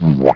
boowomp.wav